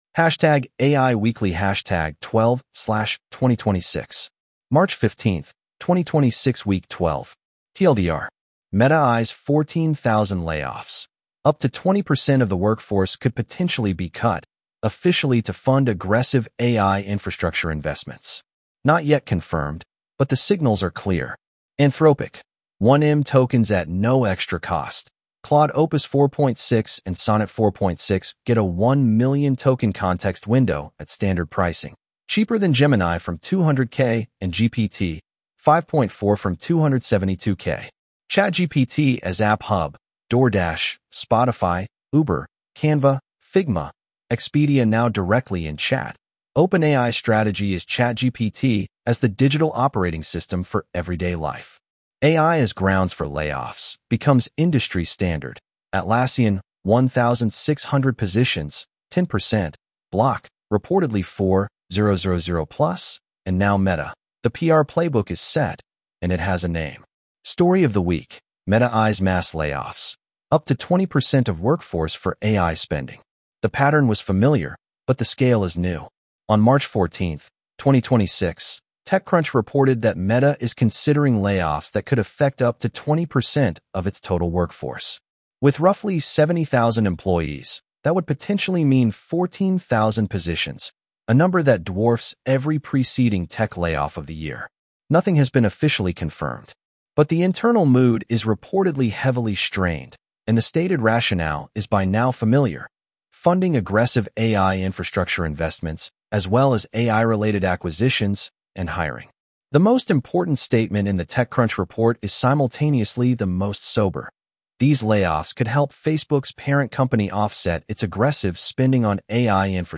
Read aloud with edge-tts (en-US-AndrewNeural)